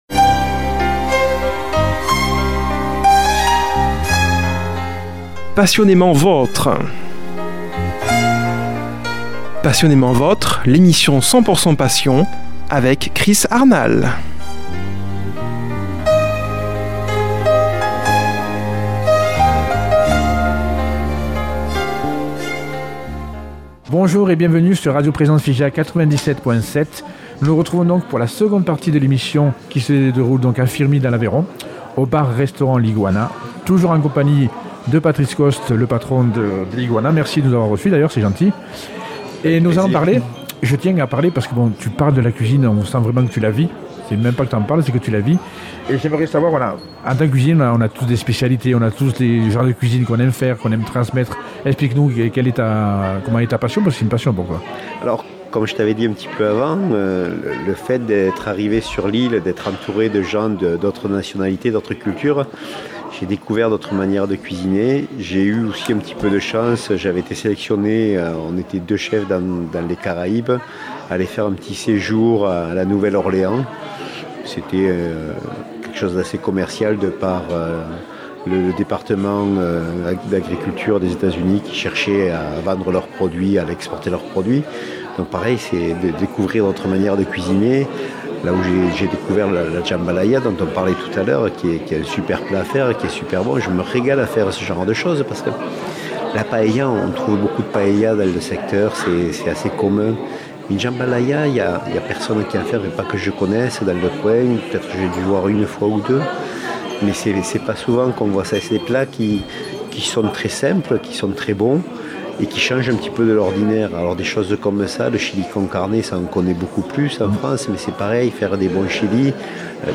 Aujourd'hui dans cette émission deuxième partie au restaurant l'Iguana à Firmi dans l'Aveyon